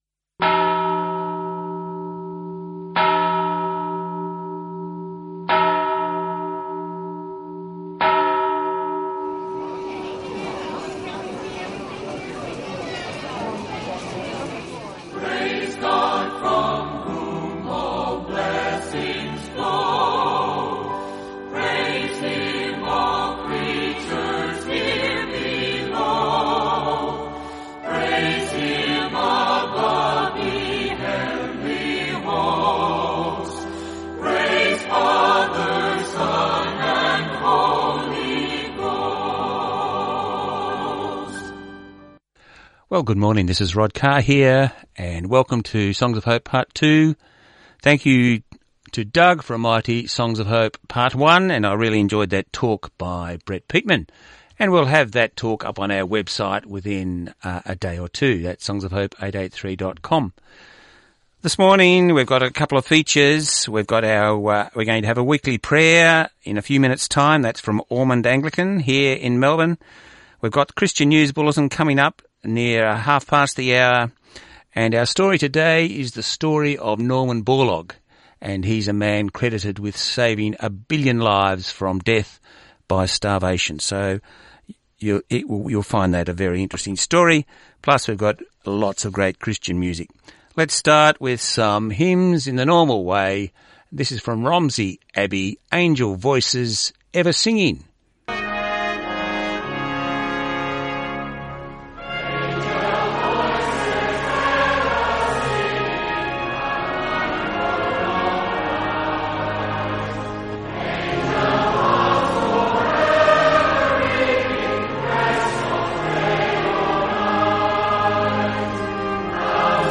Broadcast on 6May18 on Southern FM 88.3.